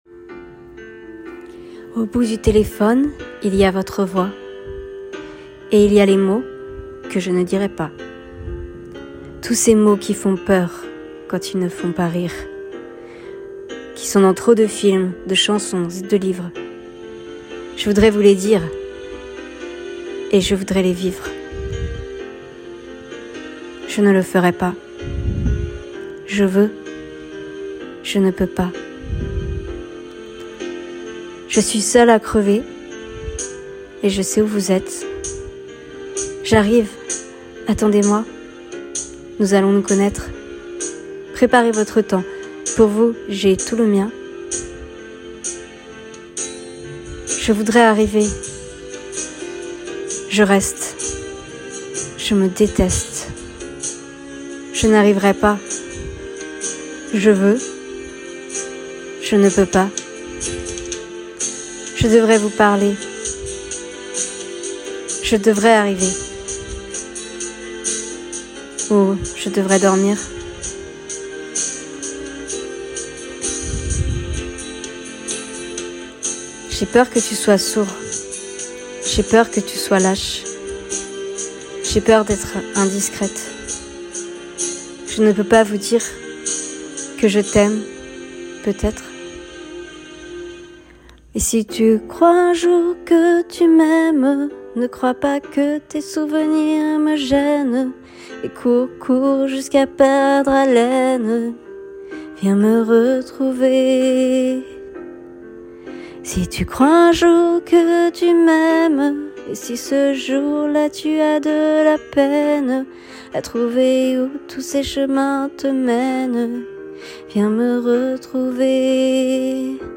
Comedienne professionnelle issue des cours Florent et licenciée en theatre
16 - 30 ans - Soprano